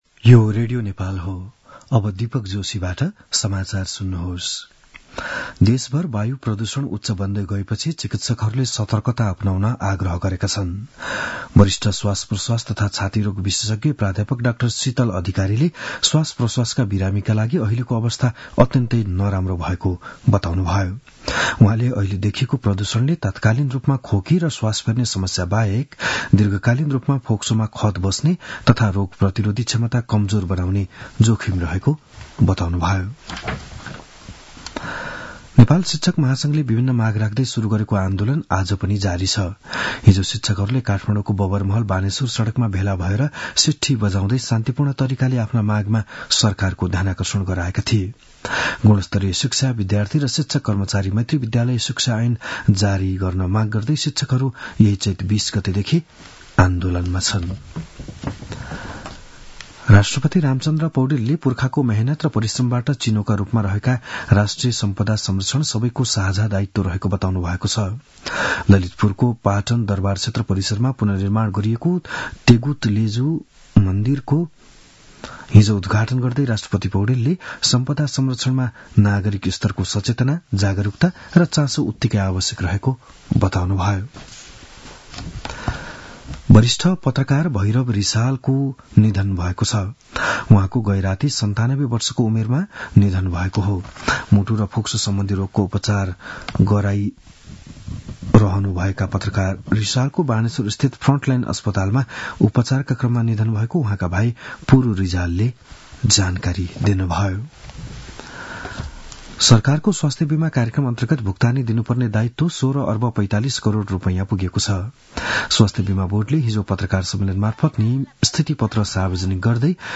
बिहान ११ बजेको नेपाली समाचार : २५ चैत , २०८१
11-am-nepali-news-.mp3